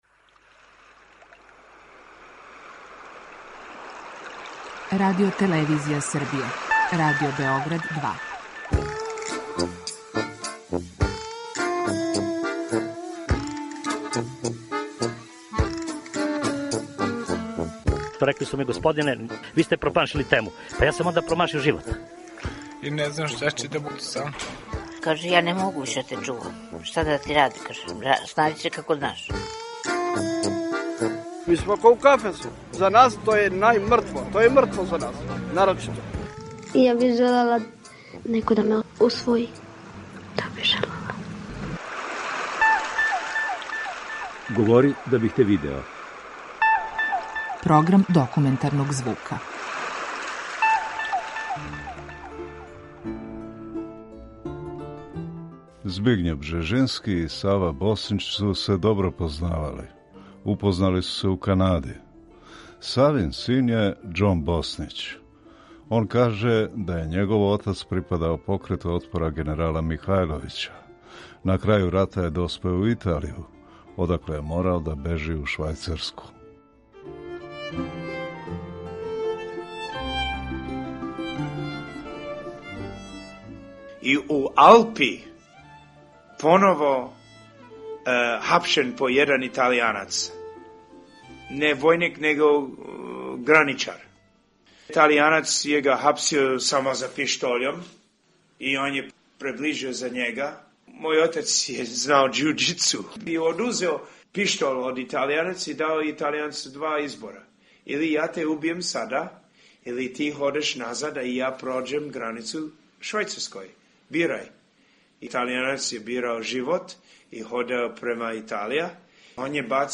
Документарни програм